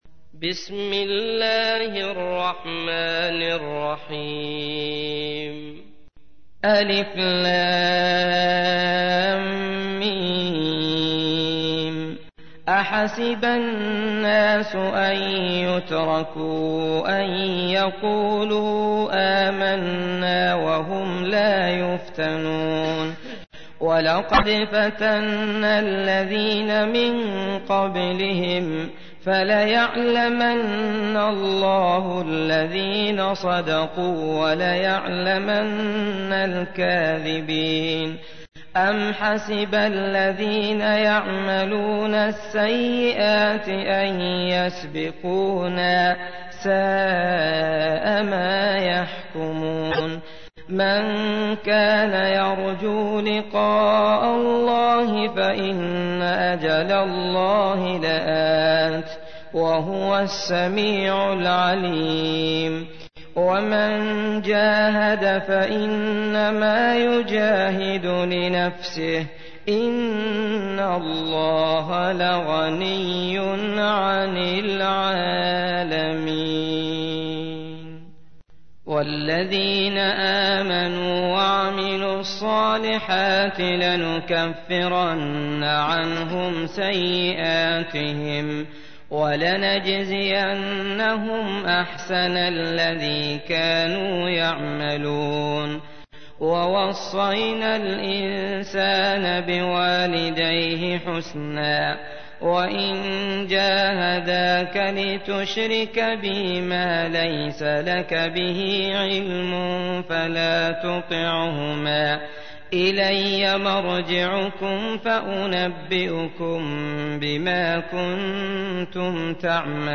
تحميل : 29. سورة العنكبوت / القارئ عبد الله المطرود / القرآن الكريم / موقع يا حسين